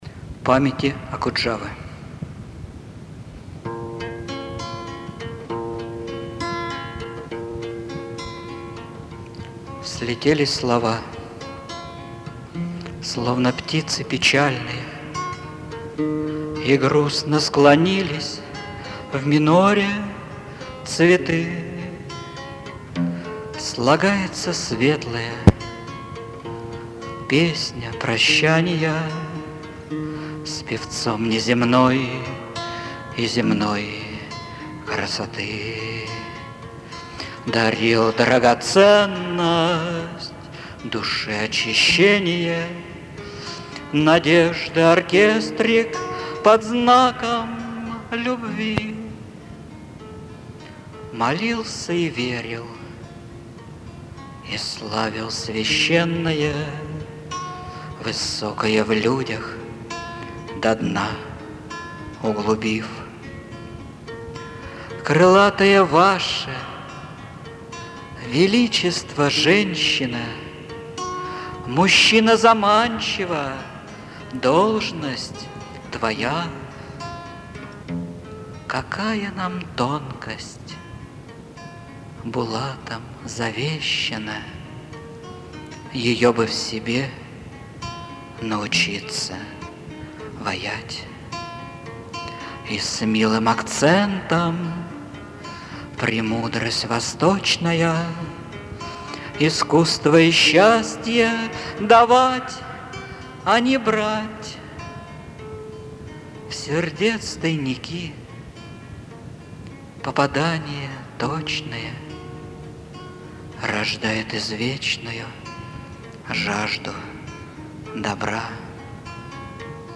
Песни